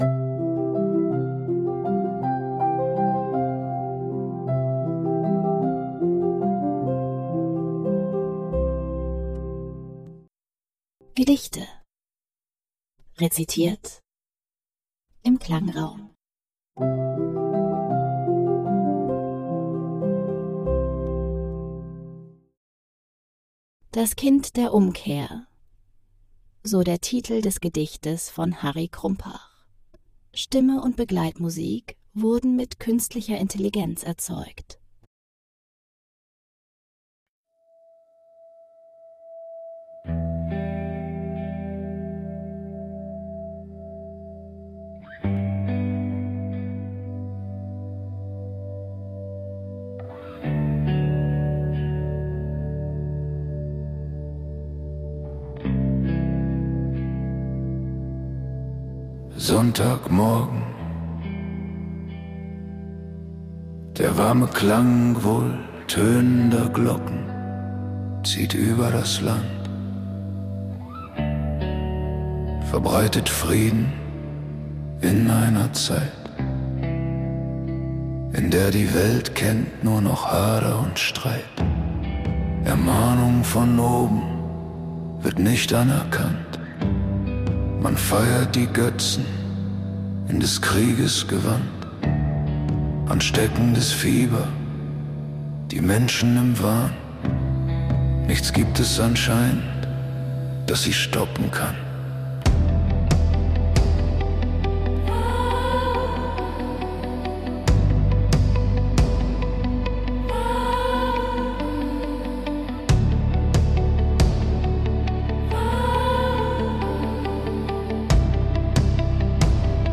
wurden mit Künstlicher Intelligenz erzeugt. 2026 GoHi (Podcast) -